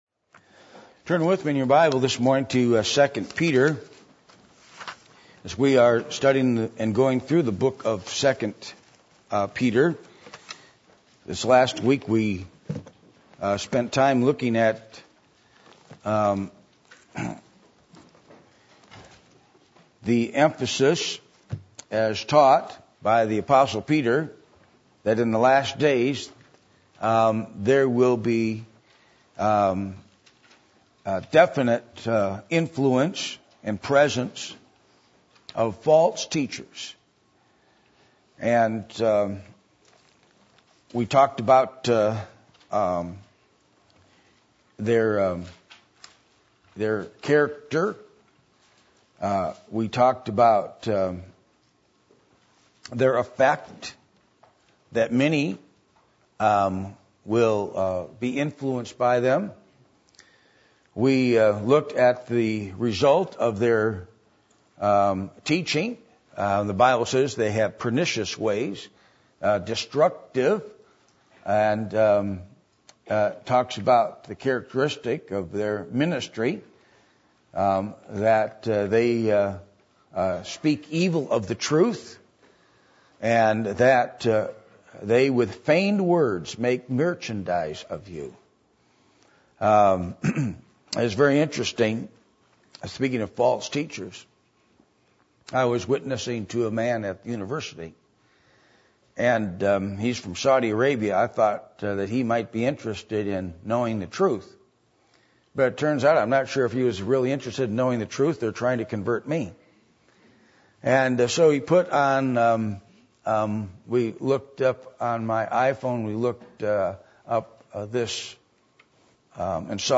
Passage: 2 Peter 2:4-9 Service Type: Sunday Morning